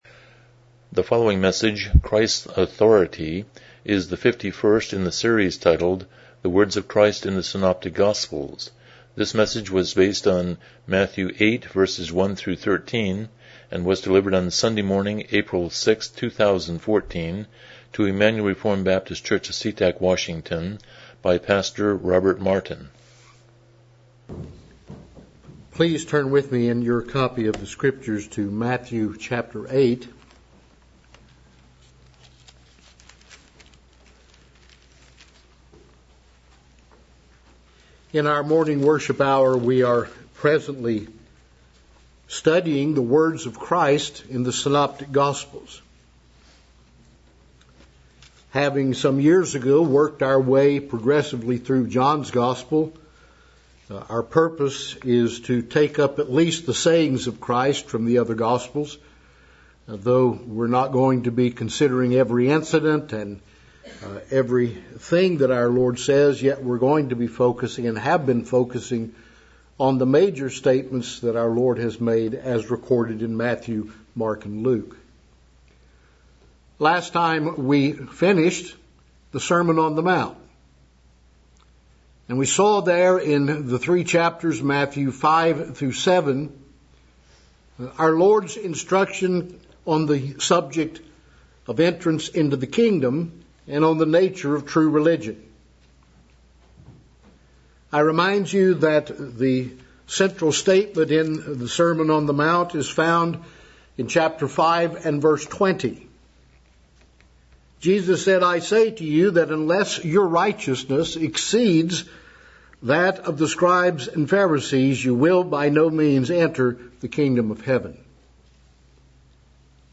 Passage: Matthew 8:1-13 Service Type: Morning Worship